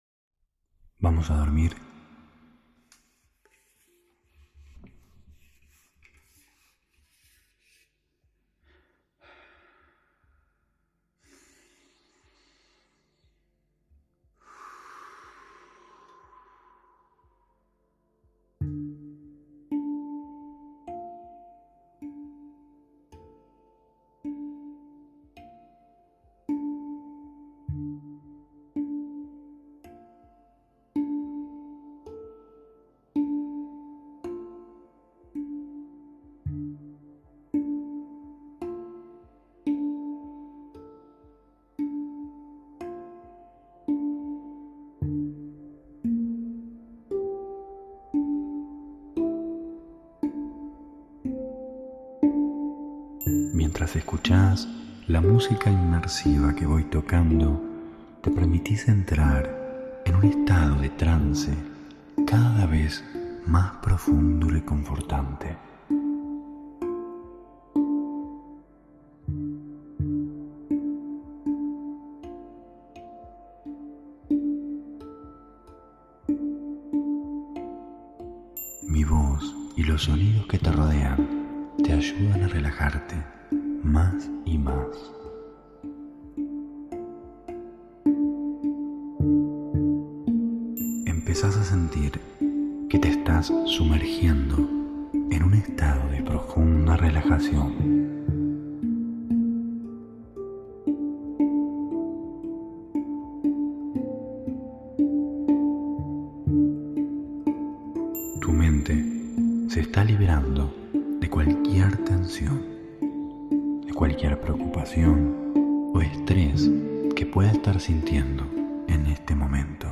Hipnosis para dormir - Abril 2023 ✨
Hipnosis guiada para dormir.